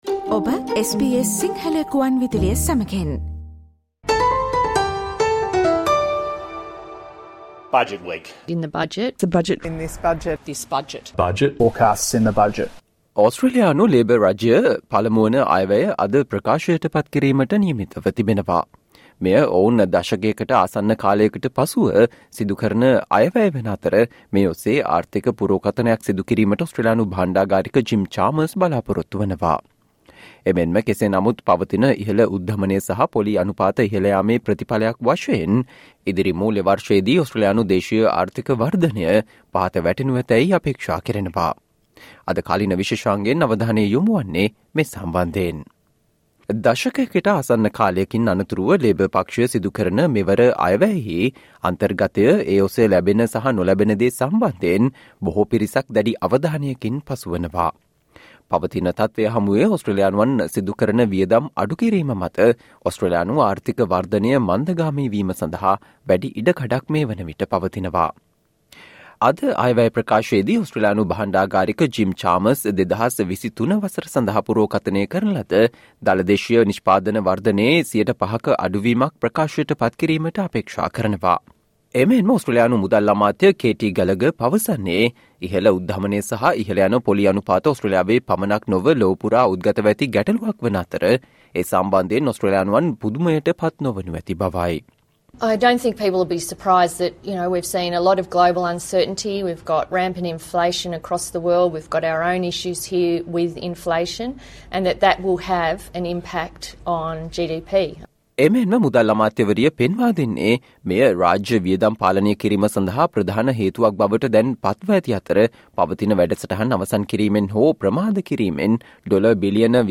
Today - 25 October, SBS Sinhala Radio current Affair Feature on Australian Treasurer plans to tackle inflation by bolstering the workforce through immigration and job training